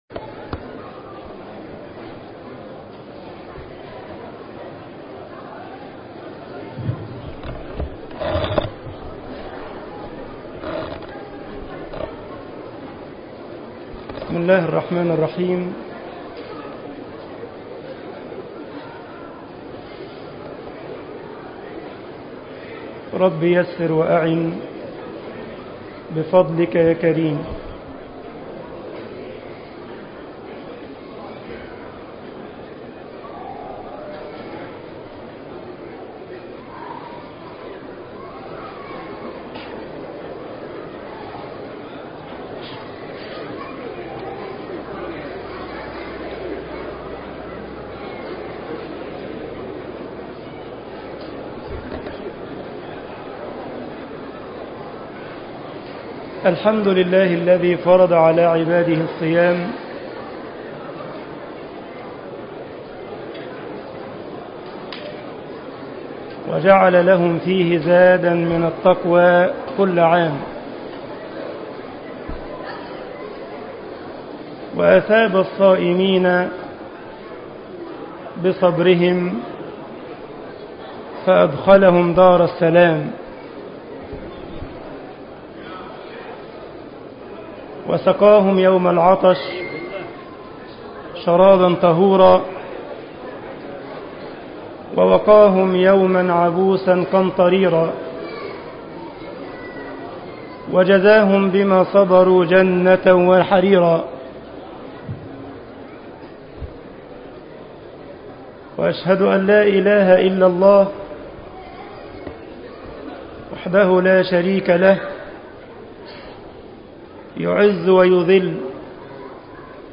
مسجد الجمعية الاسلامية بالسارلند خطبة العيد